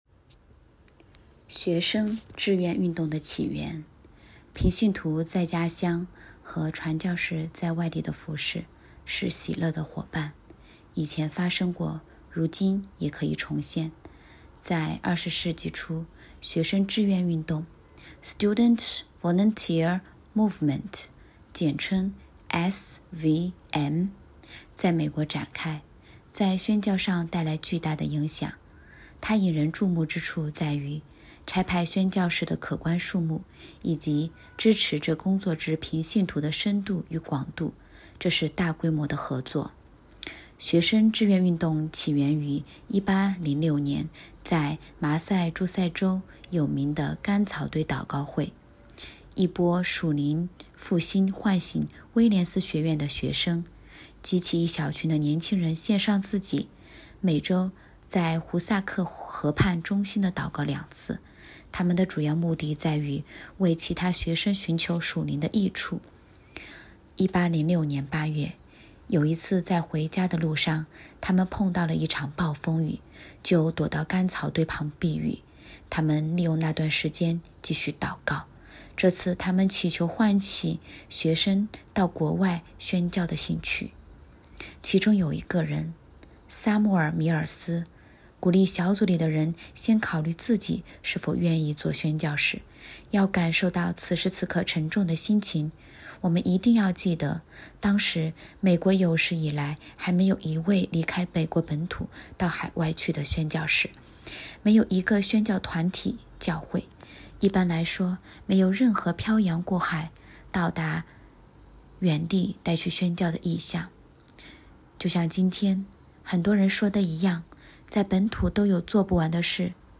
2024年9月19日 “伴你读书”，正在为您朗读：《活出热情》 欢迎点击下方音频聆听朗读内容 音频 https